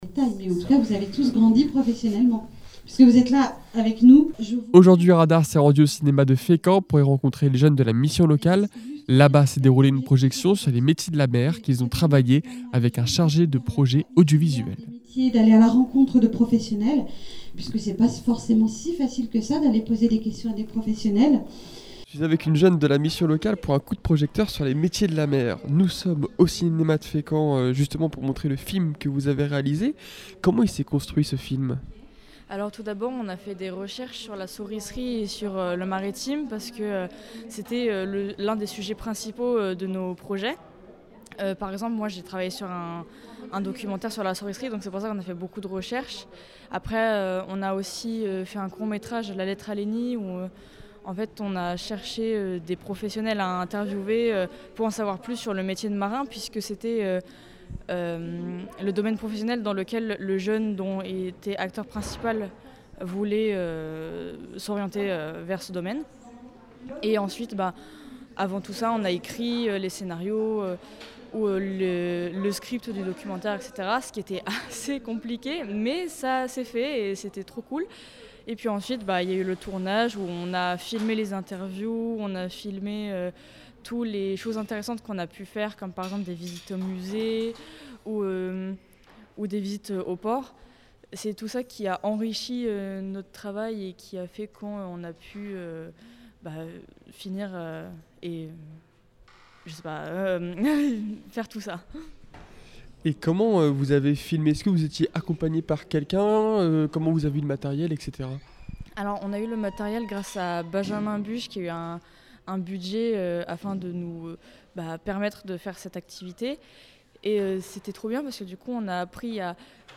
Les reportages reportage fécamp radar